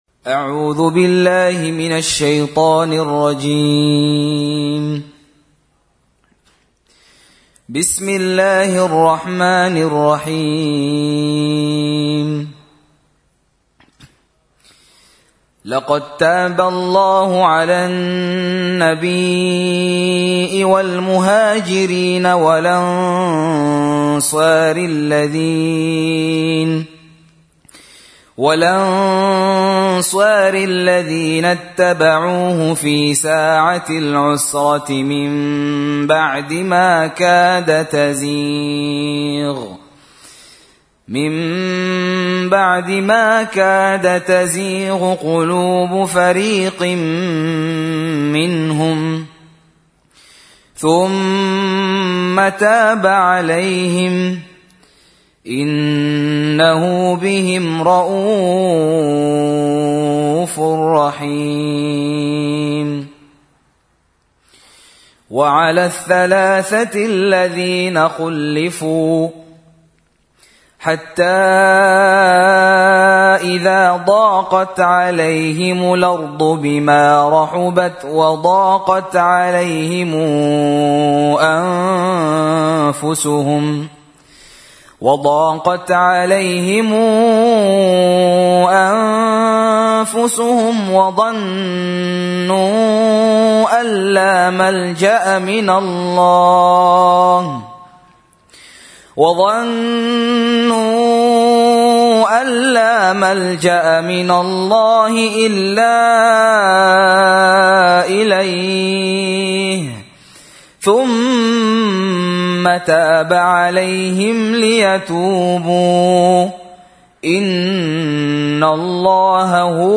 Qiraat Programme